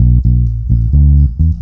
FINGERBSS4-R.wav